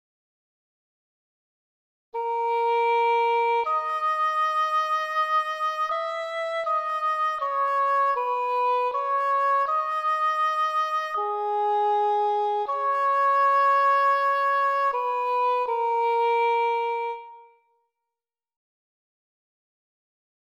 …in augmentation (twice as slow):
Fugue d# augmentation
fugue-d-augmentation.mp3